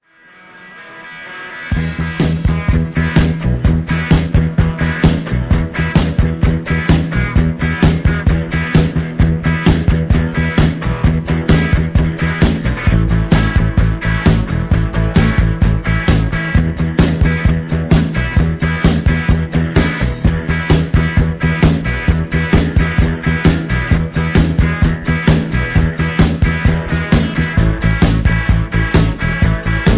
backing tracks
rock and roll, r and b, rock